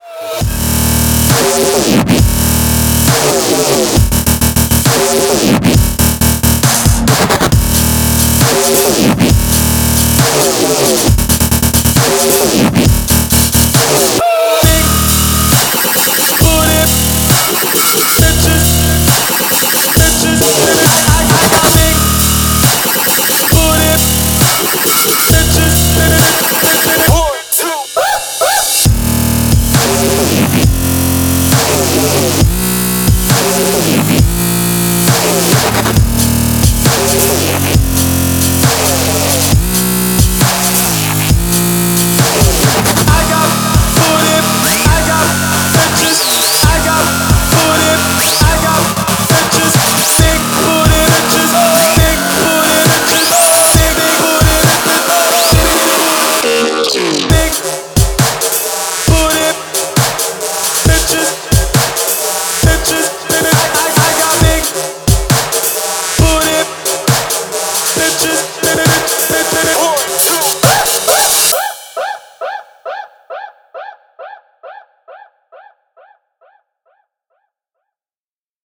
• Качество: 233, Stereo
мужской вокал
dance
мощные басы
club
Bass